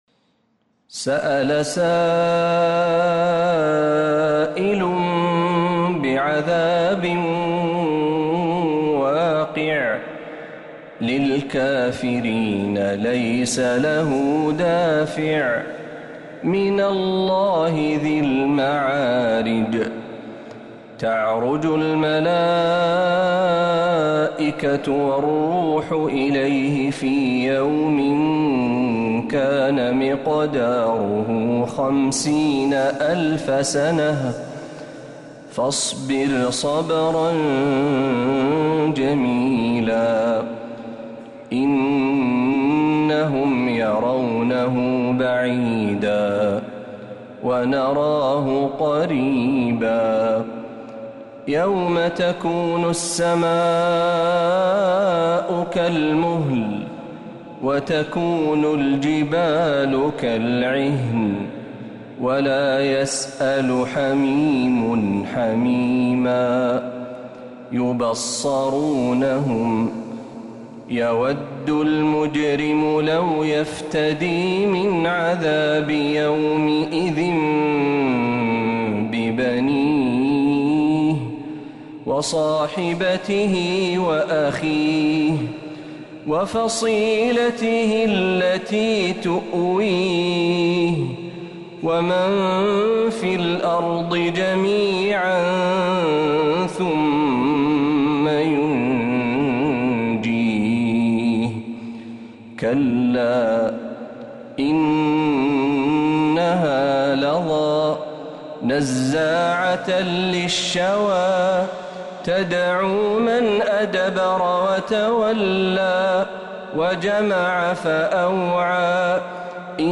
سورة المعارج كاملة من الحرم النبوي